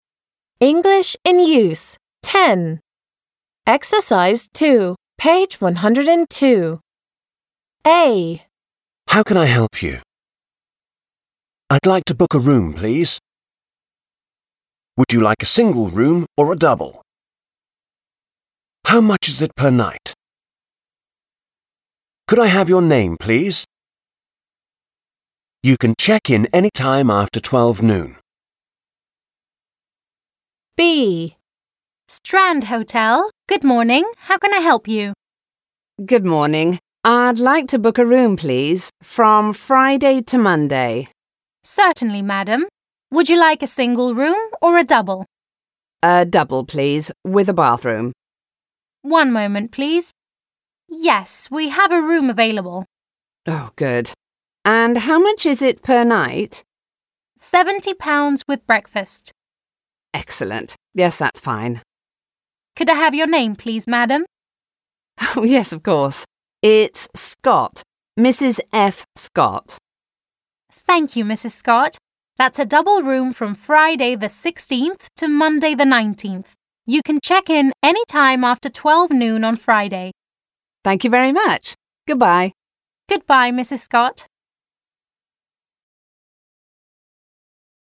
Кто их говорит: администратор гостиницы? Клиент?